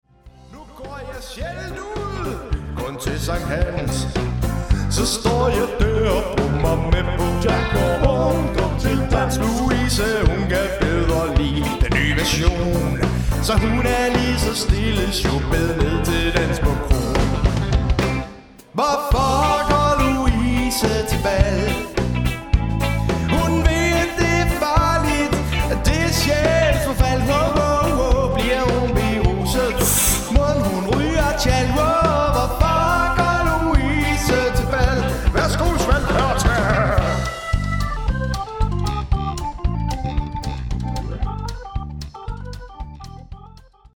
Professionel - Allround party band
• Coverband